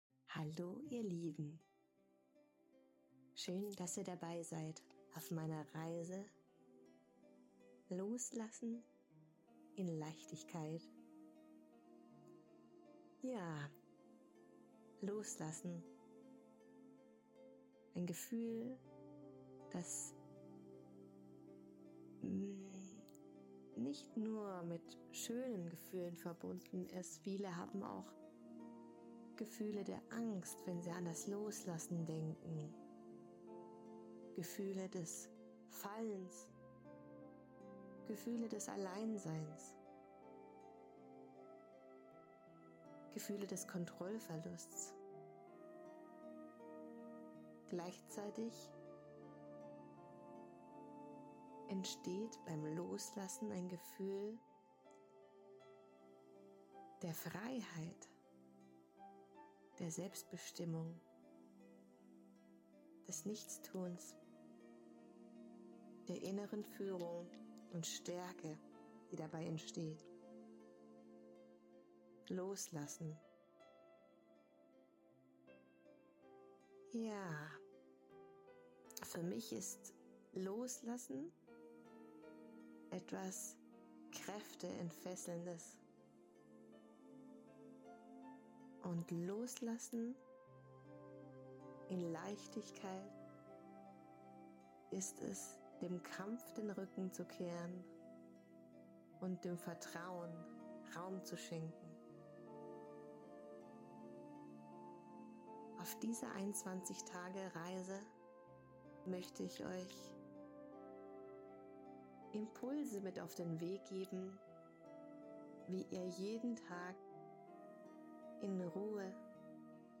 Diese hochschwingende 21-tägige Meditationsreise unterstützt dich dabei: